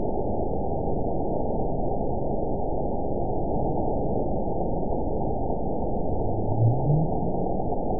event 922344 date 12/30/24 time 02:03:40 GMT (5 months, 3 weeks ago) score 9.64 location TSS-AB04 detected by nrw target species NRW annotations +NRW Spectrogram: Frequency (kHz) vs. Time (s) audio not available .wav